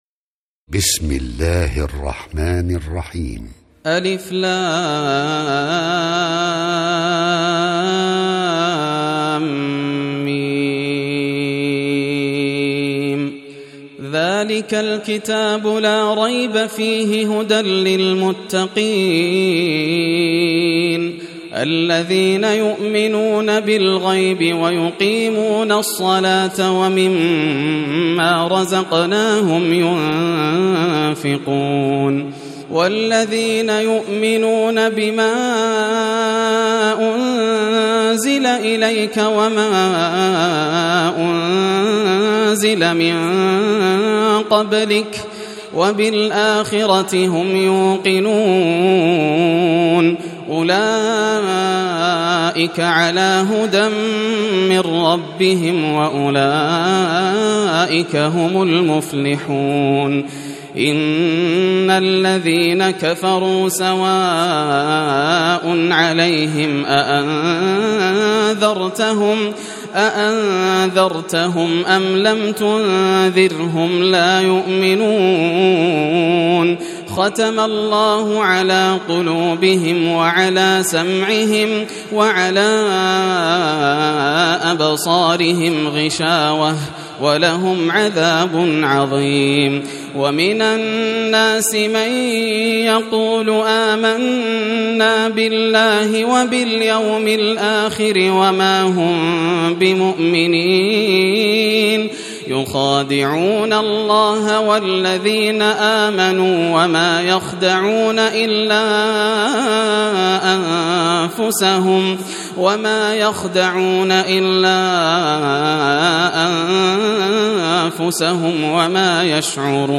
سورة البقرة > المصحف المرتل للشيخ ياسر الدوسري > المصحف - تلاوات الحرمين